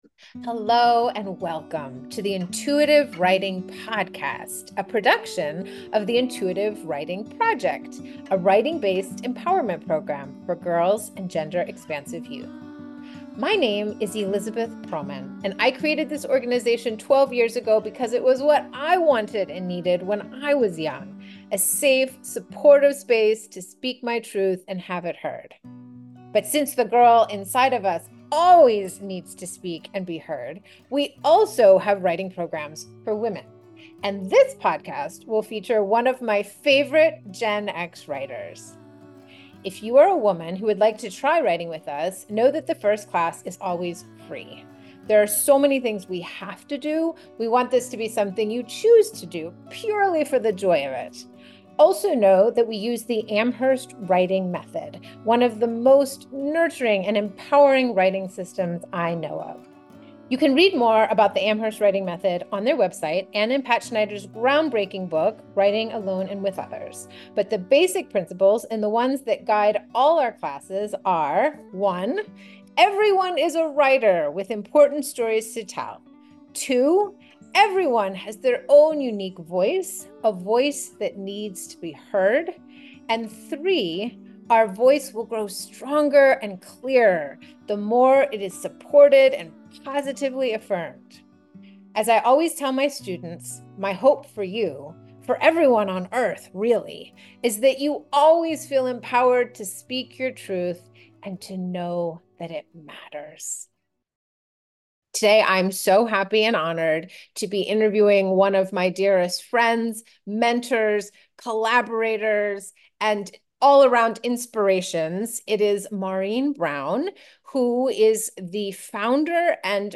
The Power of Writing to Build Self-Trust in Business: A Conversation